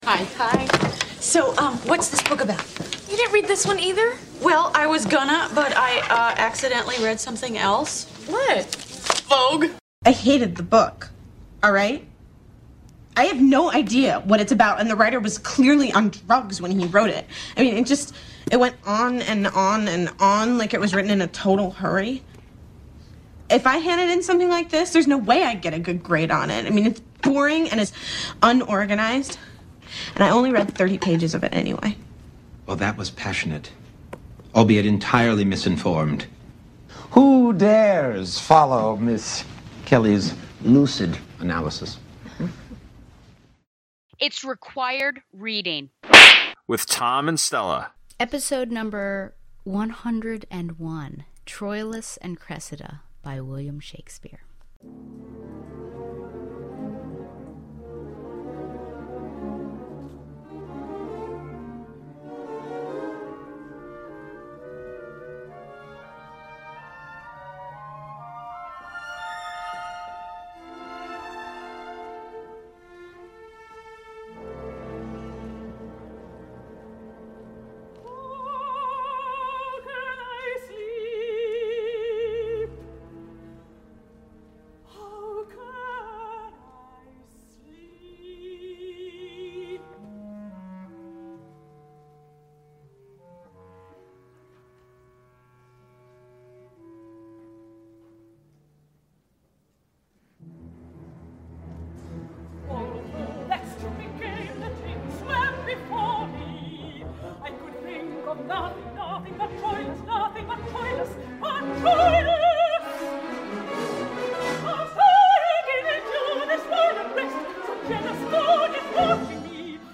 is two teachers talking about literature.